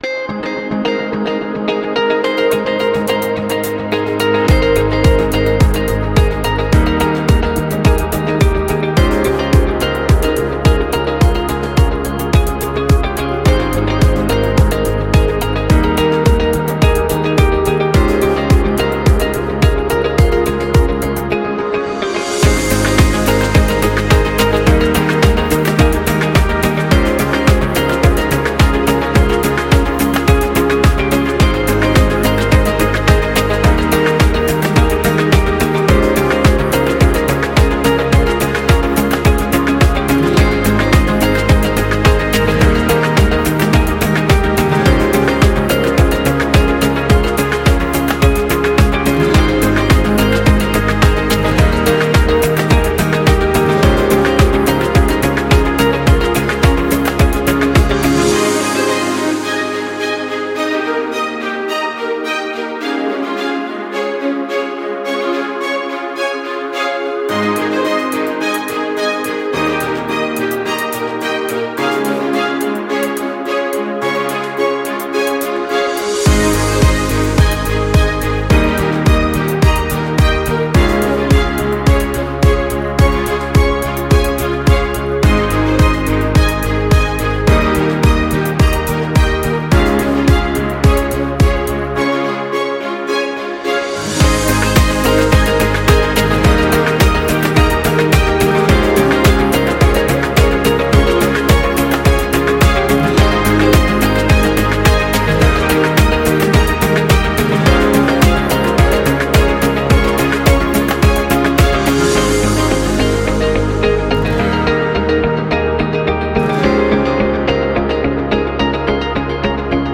Corporate